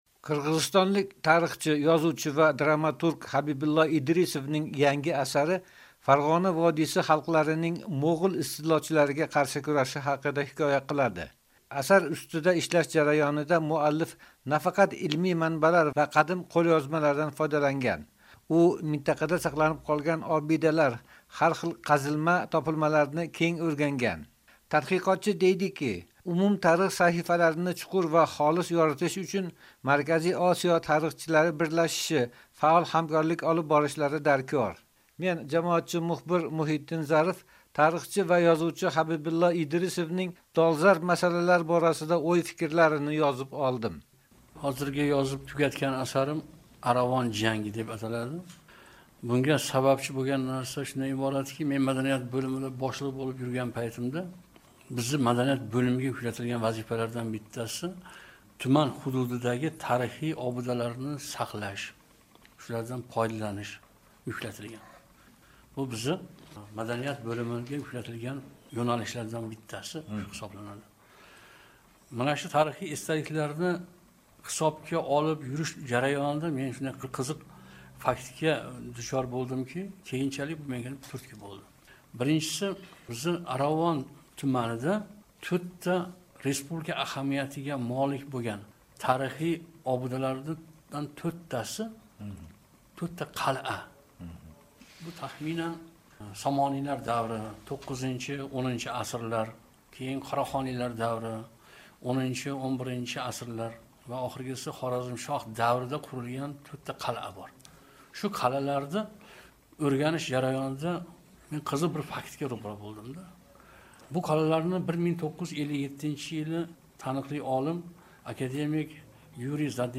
Yangi kitob: Markaziy Osiyo tarixi haqida suhbat